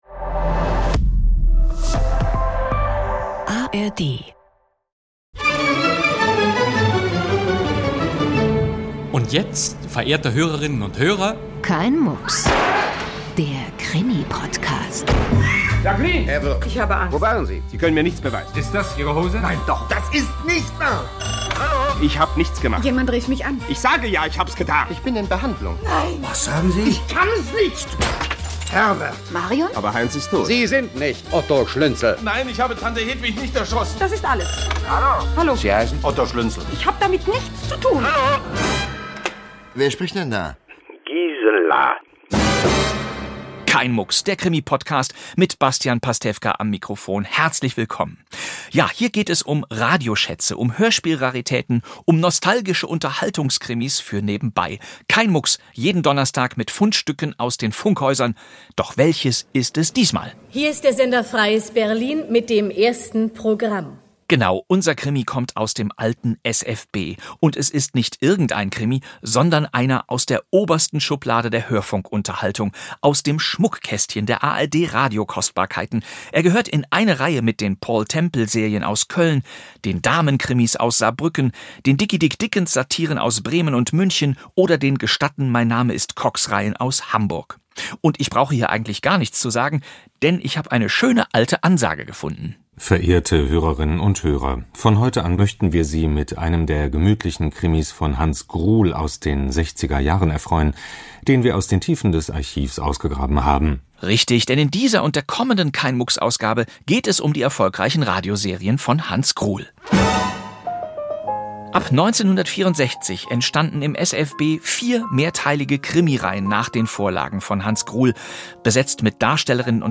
Dass aber ein blinkendes Skalpell in dessen Rücken steckt, ist ein Schock – selbst für einen Mann, der an den Umgang mit solchen Geräten gewöhnt ist… Bastian Pastewka präsentiert: Das vierte Skalpell Teil 1 von 2 Von Hans Gruhl Mit